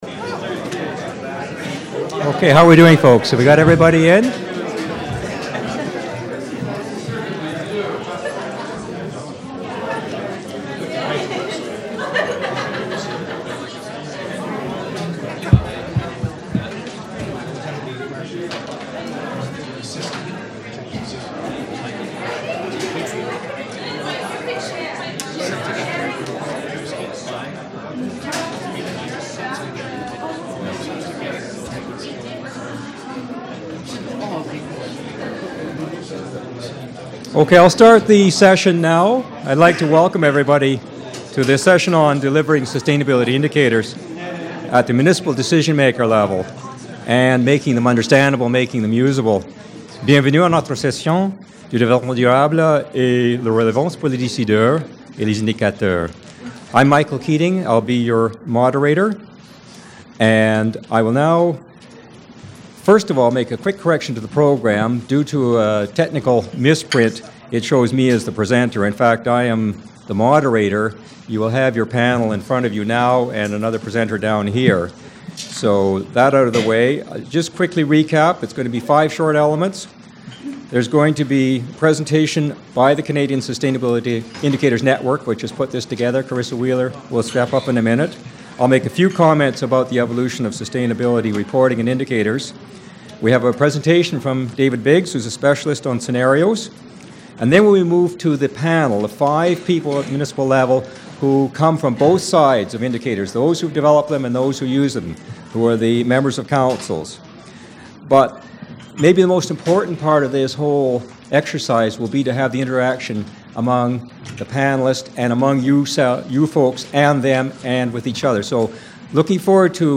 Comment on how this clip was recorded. Presentation and Panel Discussion at Federation of Canadian Municipalities Sustainable Communities National Conference